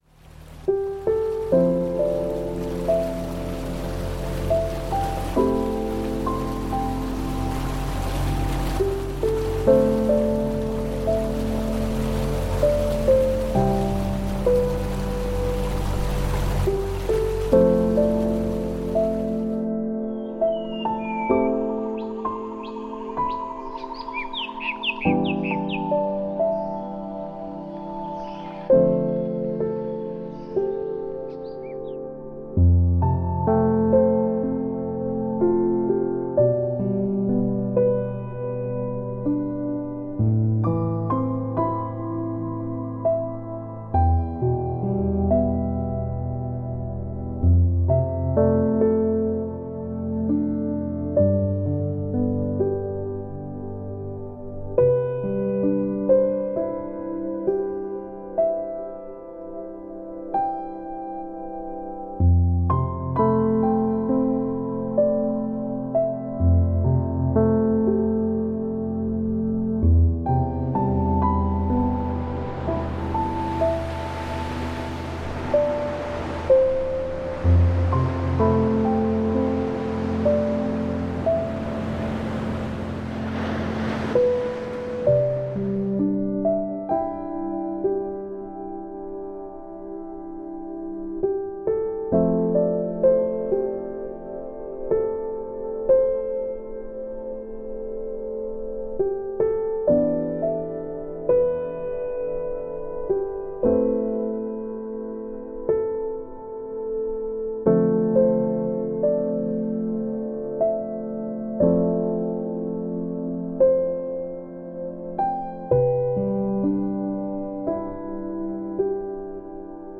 ENTSPANNENDE DÄMMERUNGS-WILDNIS: Afrika-Safari-Wildnis-Dämmerung mit Wildnis-Stimmen
Naturgeräusche